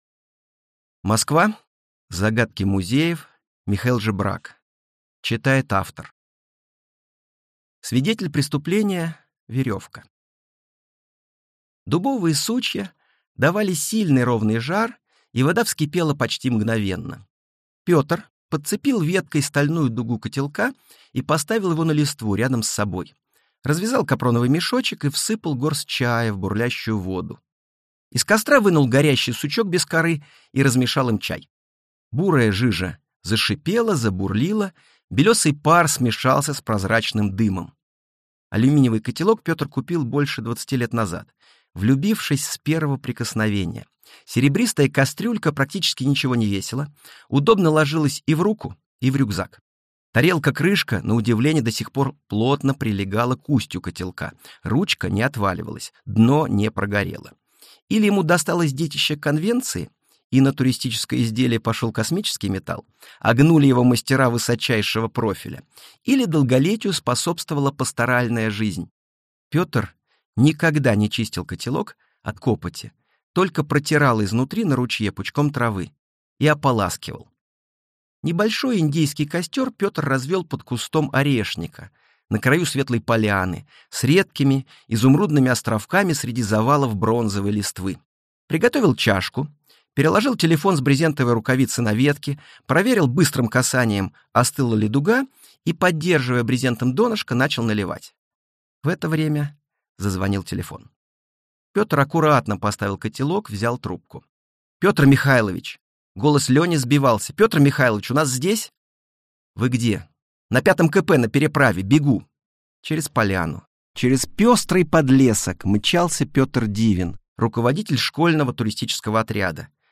Аудиокнига Москва. Загадки музеев | Библиотека аудиокниг